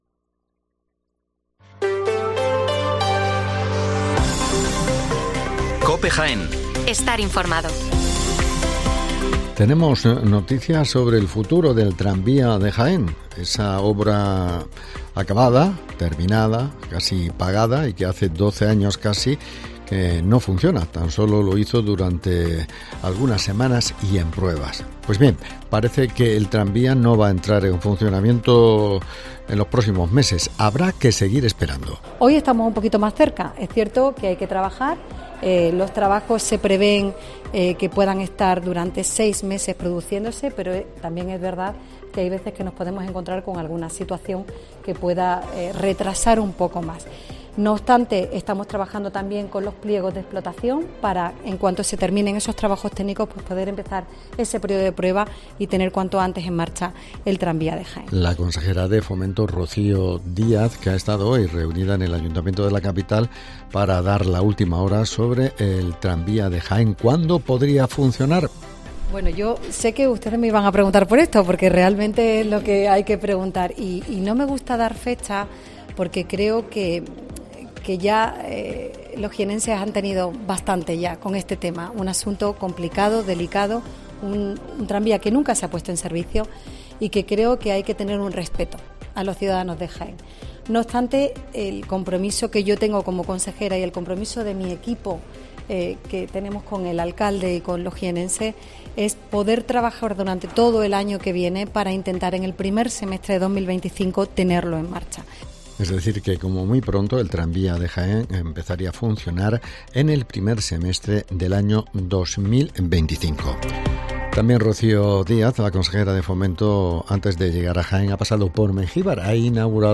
Las noticias locales del 9 de octubre de 2023